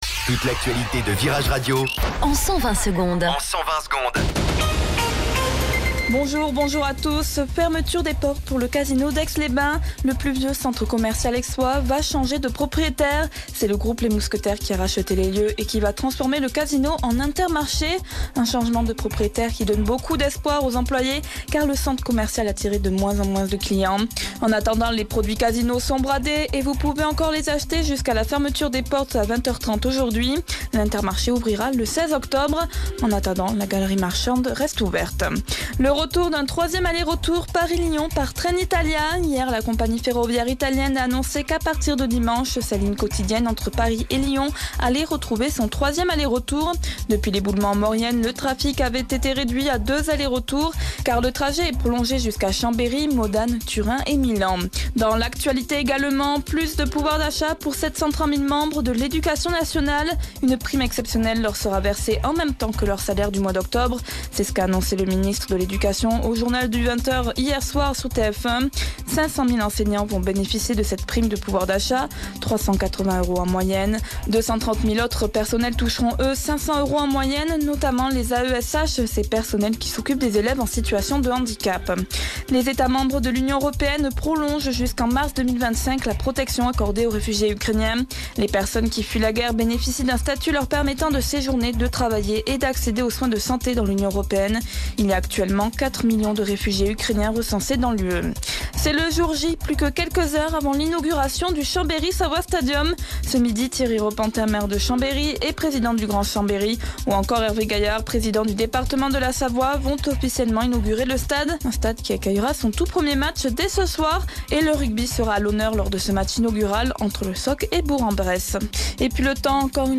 Flash Info Chambéry 29 Septembre 2023 Du 29/09/2023 à 07h10 Flash Info Télécharger le podcast Partager : À découvrir Alerte Canicule : Le Eddie’s Dive Bar d’Iron Maiden débarque en France !